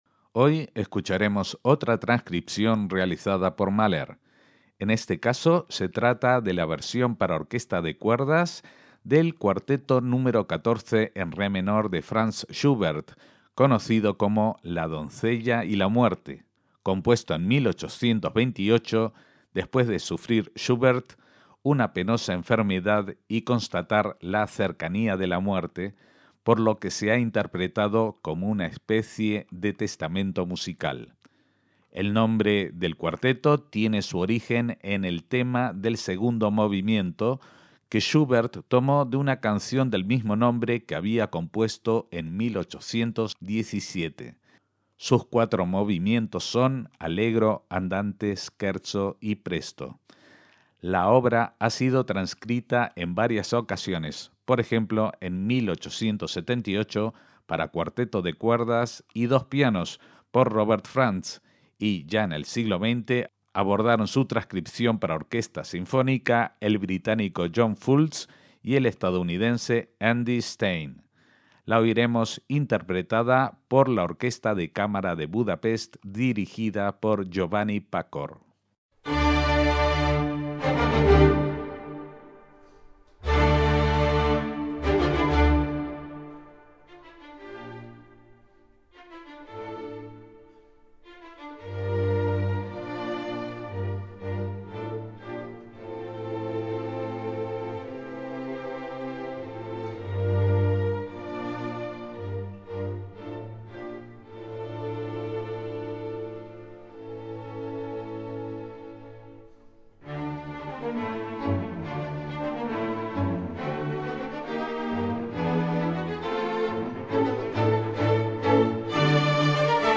“La doncella y la muerte” de Schubert, en arreglo para orquesta de cuerdas
Orquesta de Cámara